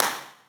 TS - CLAP (4).wav